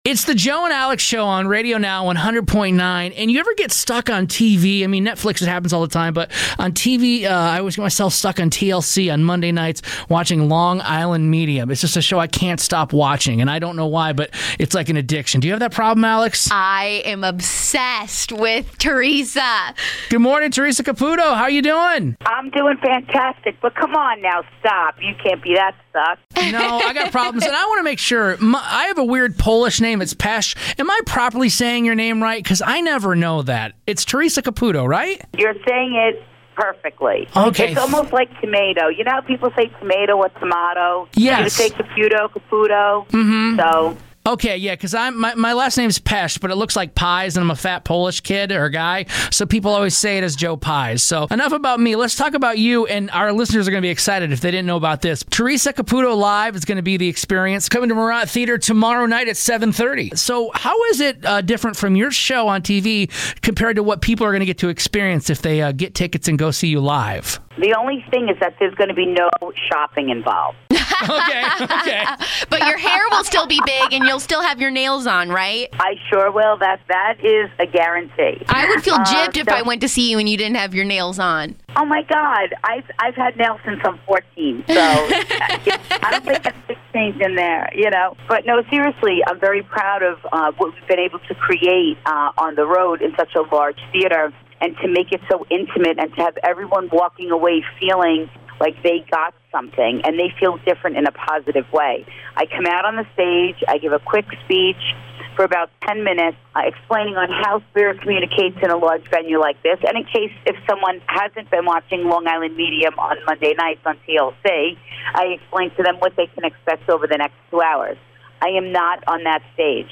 We interviewed TLC's "The Long Island Medium" on the show today.